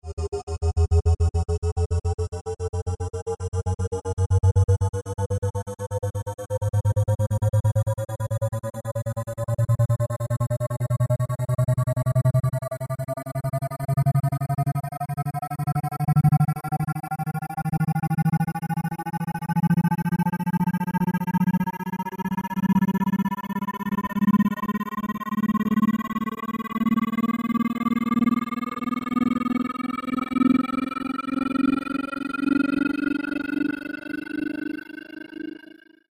Pulsing
Amplifier Hum Ascend